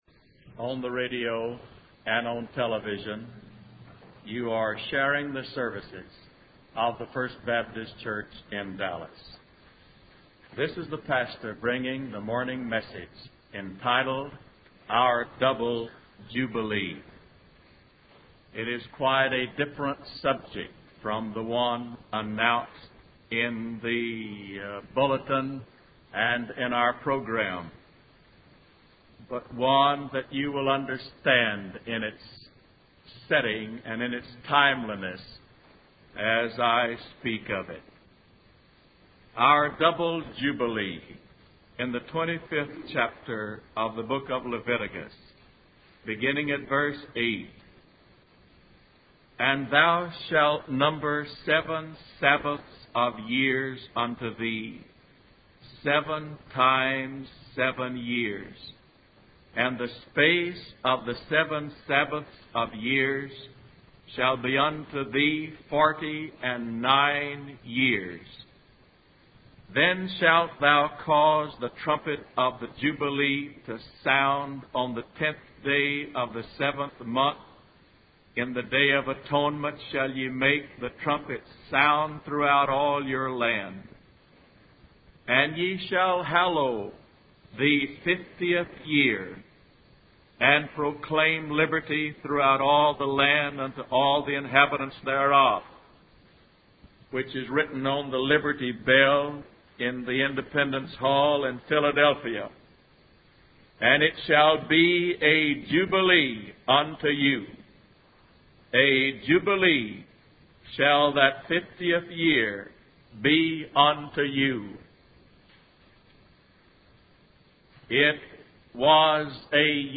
W. A. Criswell Sermon Library | Our Double Jubilee